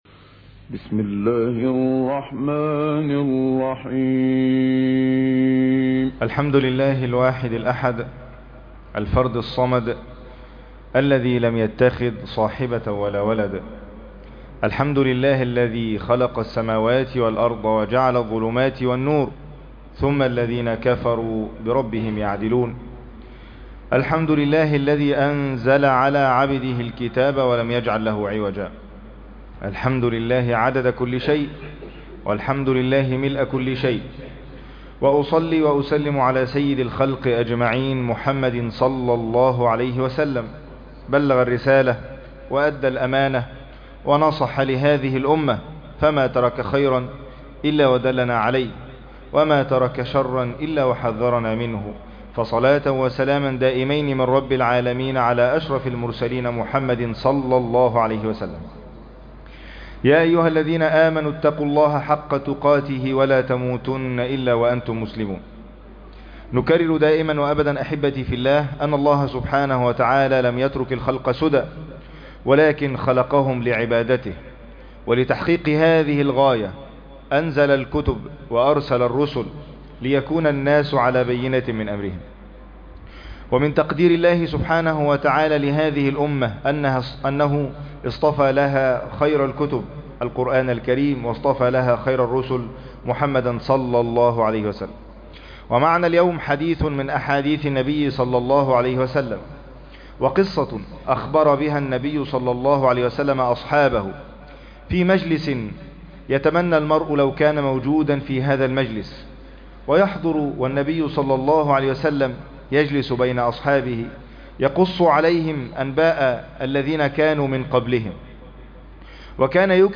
عنوان المادة حديث الأبرص والأقرع والأعمى | خطبة جمعة تاريخ التحميل الجمعة 6 سبتمبر 2024 مـ حجم المادة 15.00 ميجا بايت عدد الزيارات 206 زيارة عدد مرات الحفظ 99 مرة إستماع المادة حفظ المادة اضف تعليقك أرسل لصديق